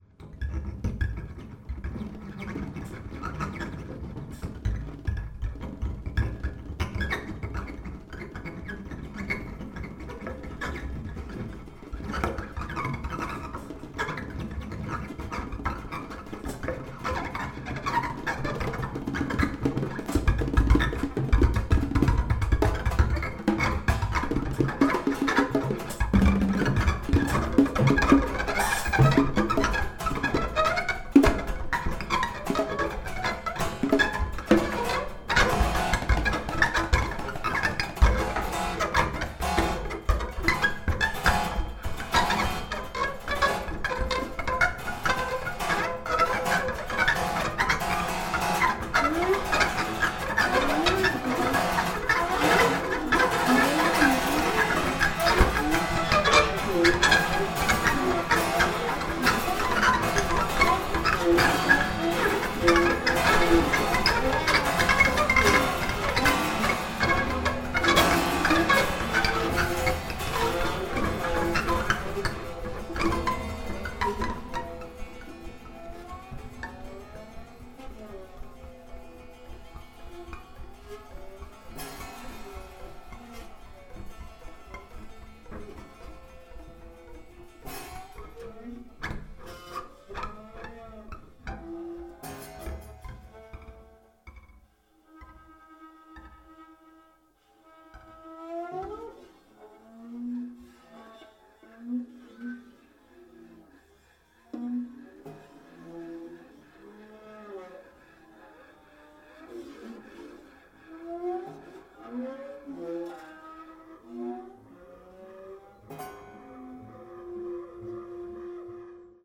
トリオ編成のライブ録音!!
dr & perc
live concert
free and structured improvisations